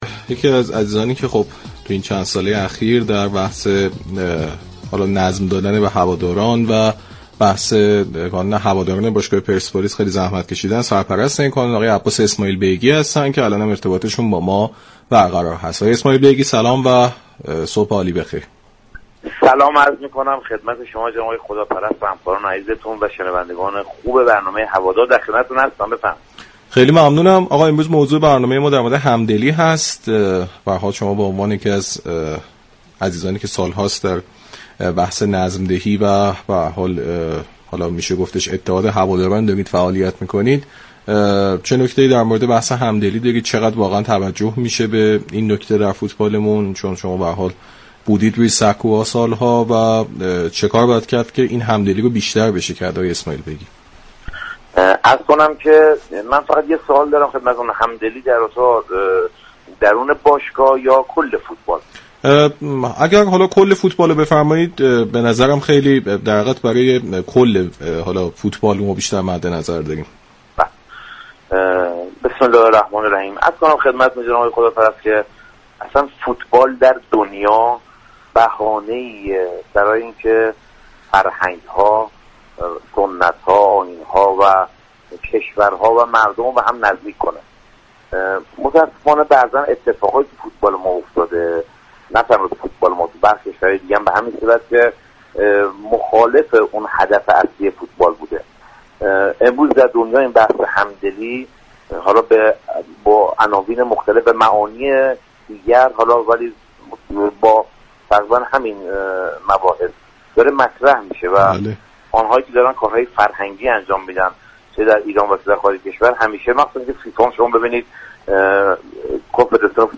در گفتگو با برنامه هوادار رادیو ورزش